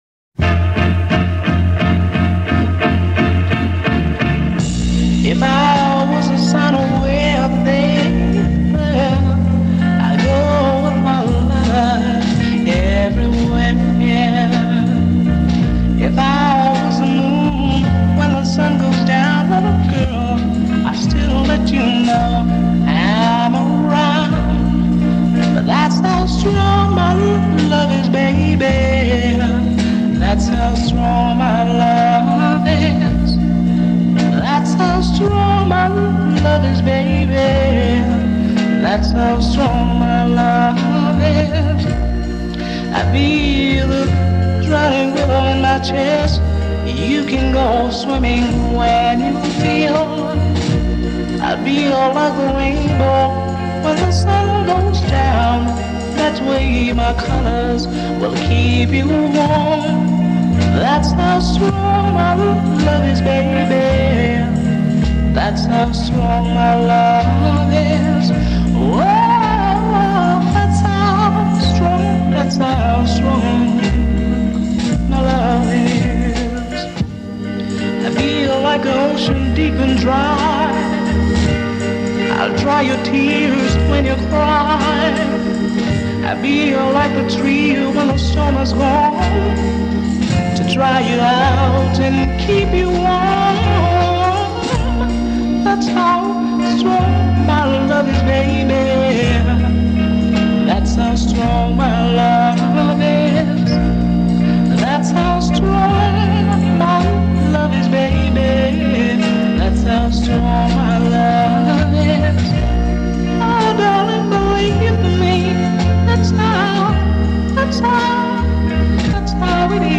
The opening guitar stabs give way to spiritual arpeggios
organ